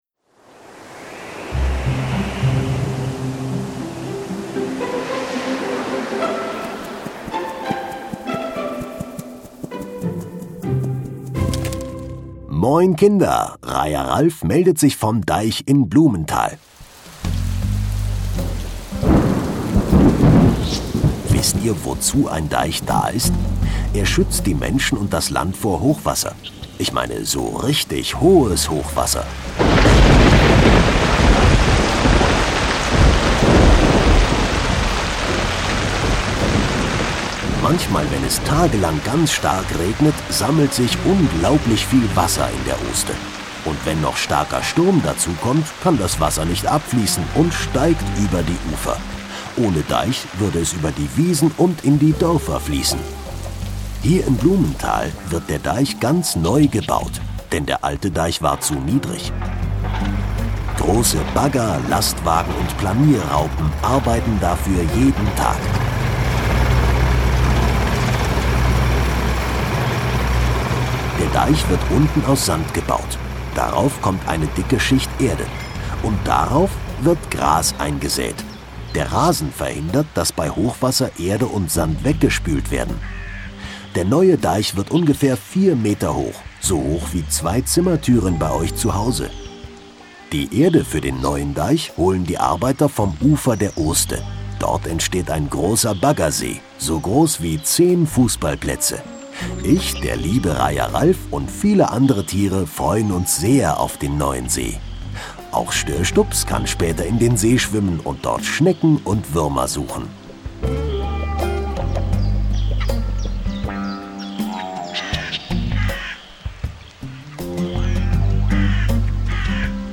Deichbau Blumenthal - Kinder-Audio-Guide Oste-Natur-Navi